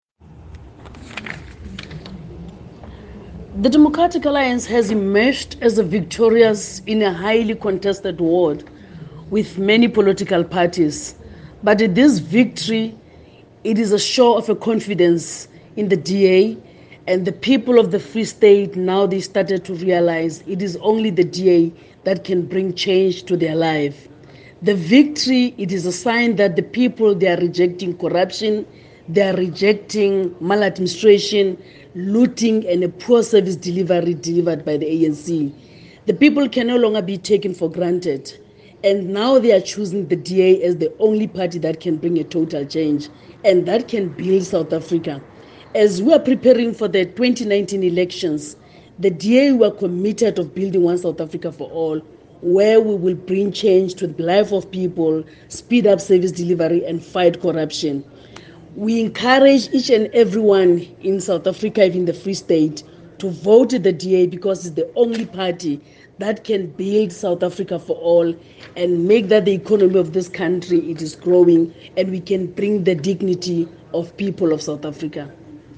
Find attached soundbites in